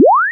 描述：正弦波。220至2000赫兹扫频用Audacity创建